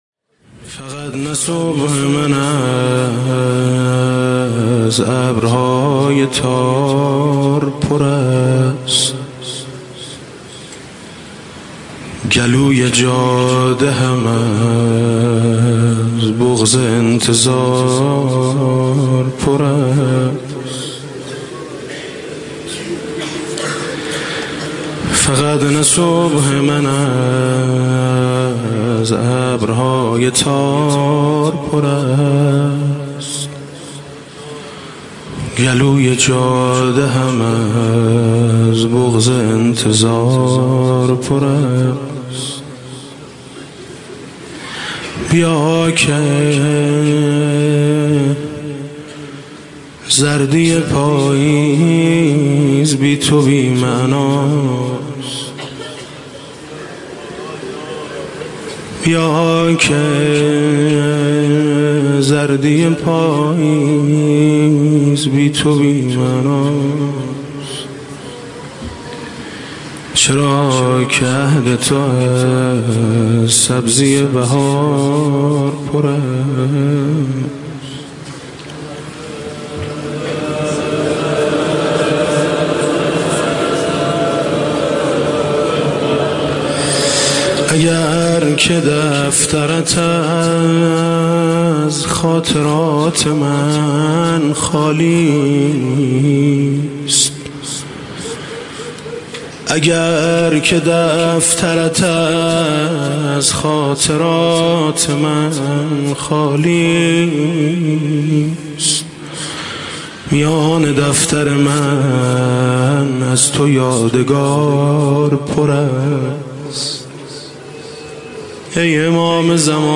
شب دوم محرم97